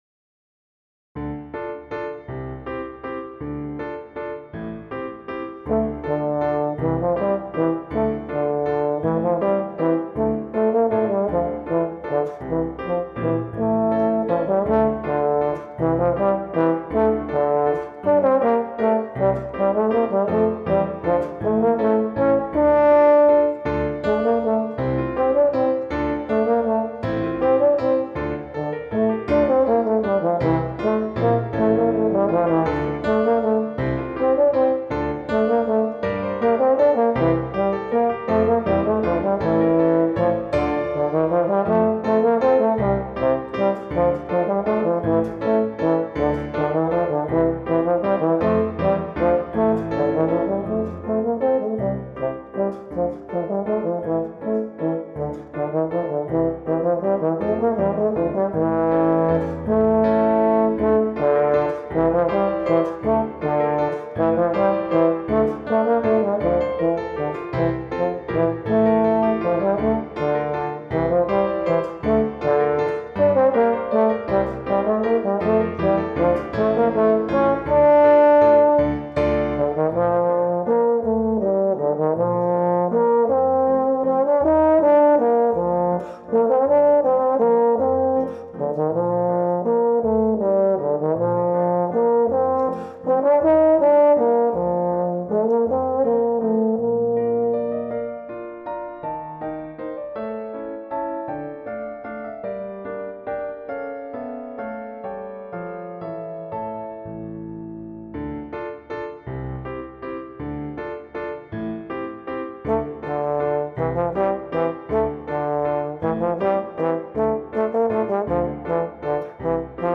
Voicing: Trombone or Euphonium and Piano